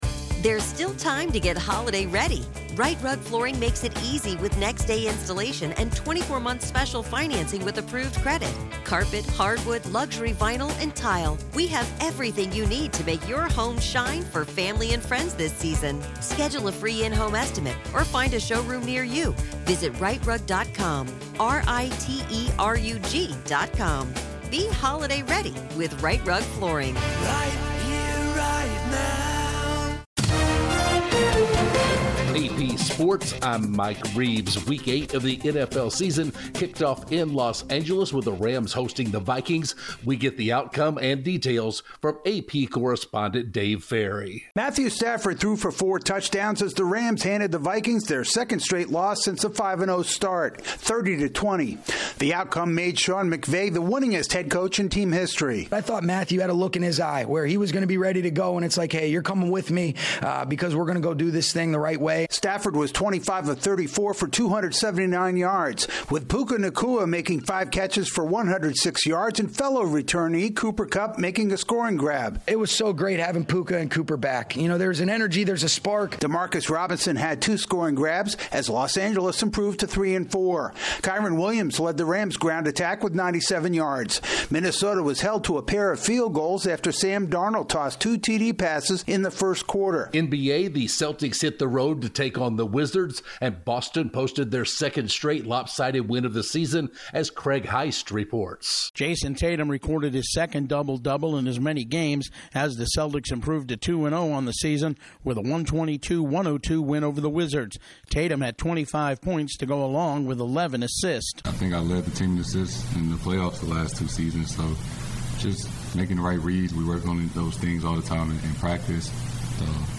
The Vikings lose their second straight game, Klay Thompson has a successful Mavericks debut, the NBA is investigating why a 76ers star sat out the season opener, and Panthers goaltender Sergei Bobrovsky records a milestone victory. Correspondent